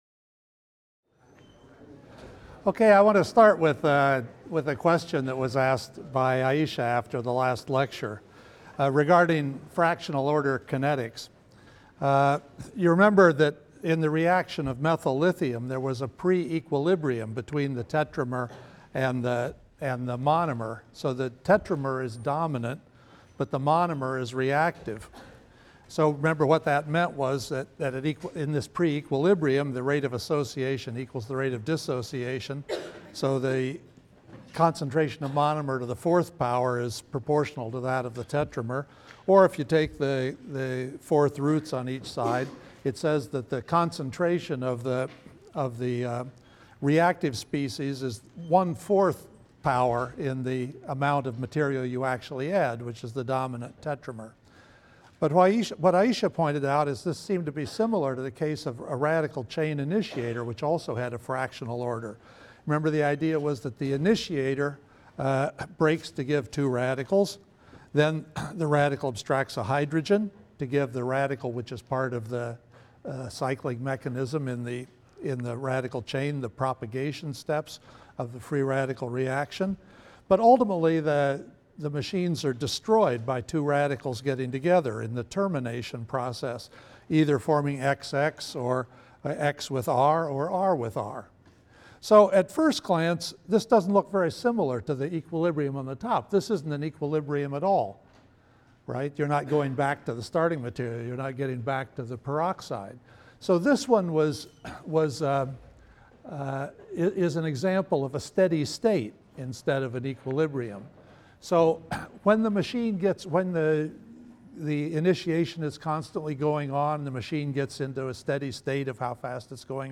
CHEM 125b - Lecture 4 - Electronegativity, Bond Strength, Electrostatics, and Non-Bonded Interactions | Open Yale Courses